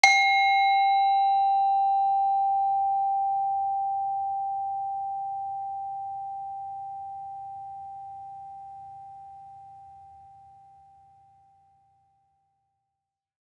Saron-2-G4-f.wav